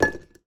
Food & Drink, Tableware, Apple Slice, Drop On Plate 01 SND20937.wav